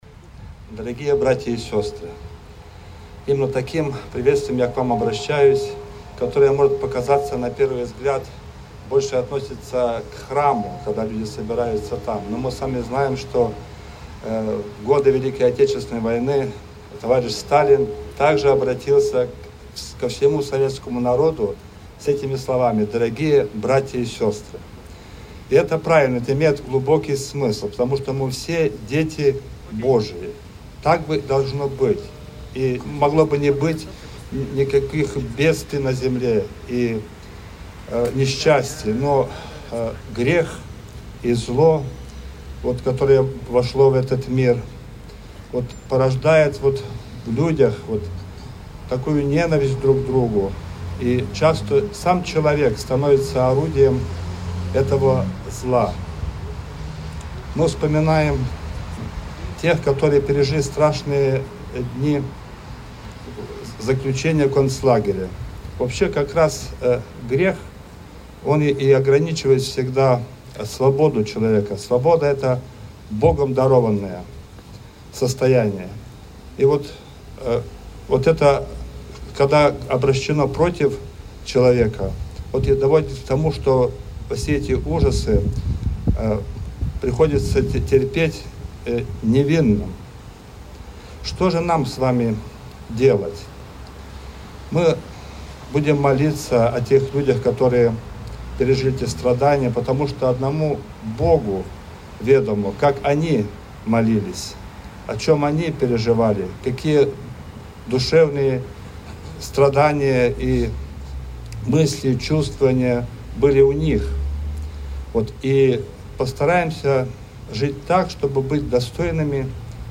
Мероприятие прошло в Красном Селе, в Ландшафтном парке, у памятника узникам фашистских концлагерей, у подножия которого заложена капсула с землей из нескольких лагерей смерти.